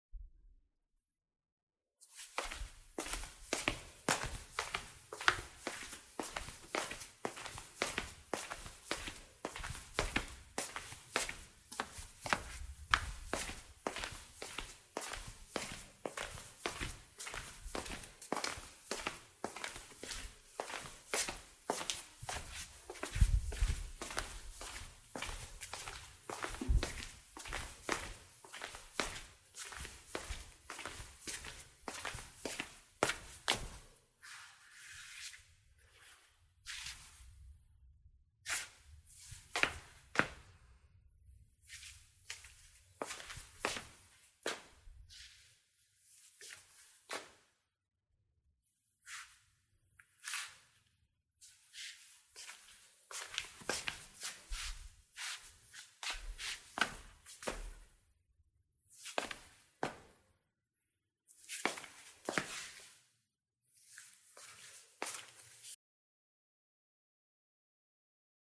拖鞋脚走在瓷砖地板上
描述：在瓷砖地板上行走的拖鞋，用于走楼梯。
标签： 拖鞋 拖鞋 中空 瓷砖 地板 步行
声道立体声